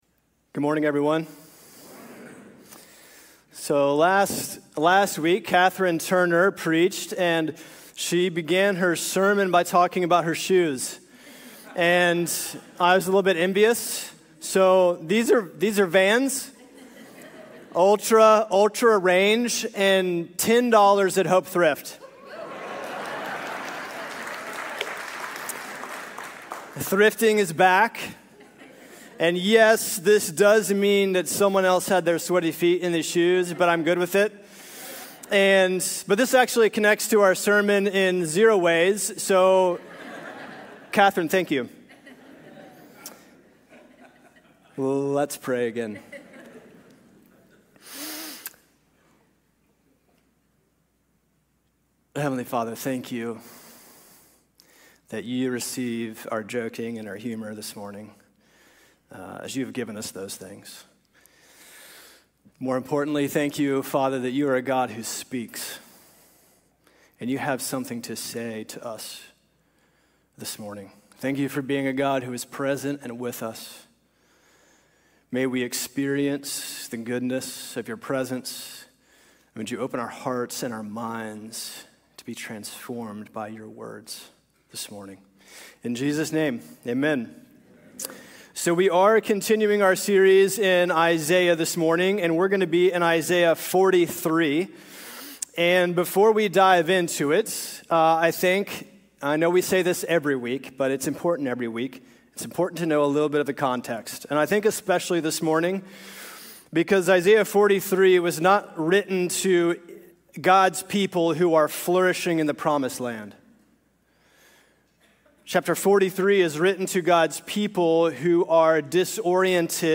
A weekly podcast with current sermons from HOPE Church in Richmond, Virginia.